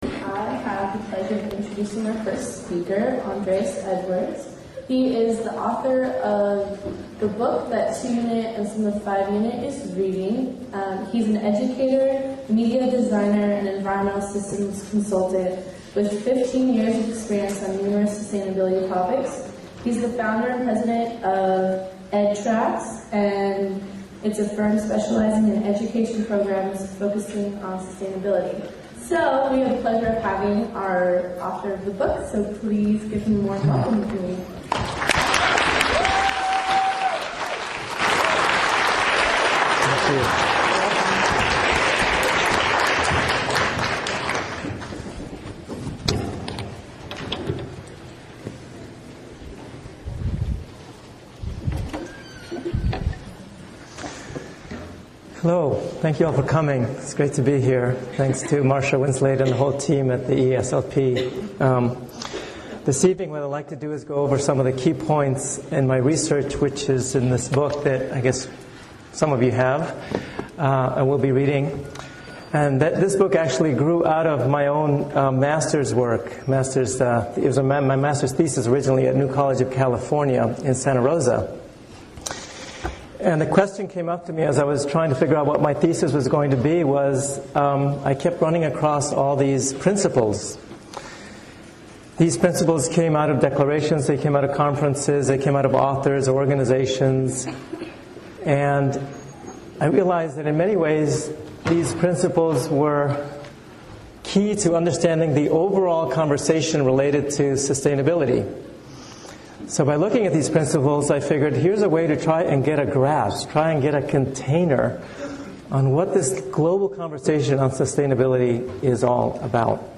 Presentation about the sustainability movement and initiatives to students from UC Santa Cruz, followed by Q/A.